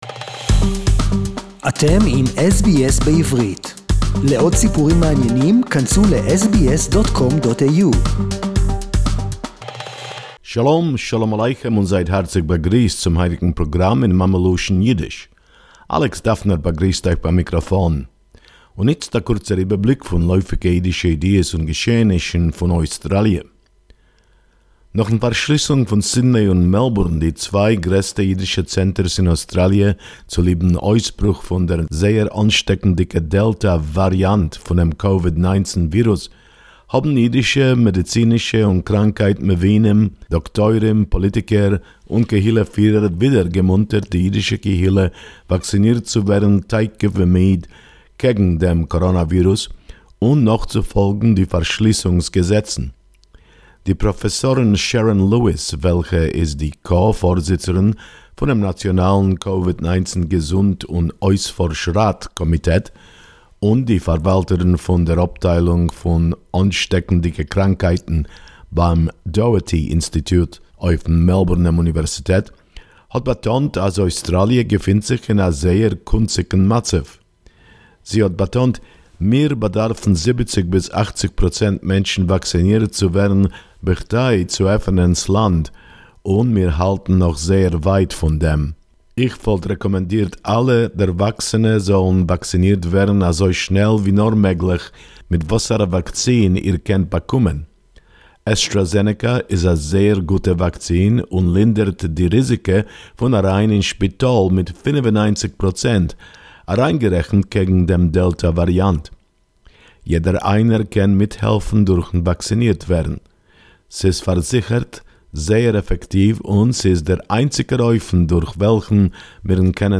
SBS Yiddish report